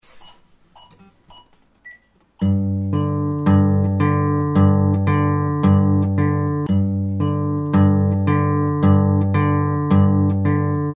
Övningen inleds genom att spela G dvs. tredje bandet på E strängen och öppen D sträng i ett bestämt tempo.
Fingerstyle 1.mp3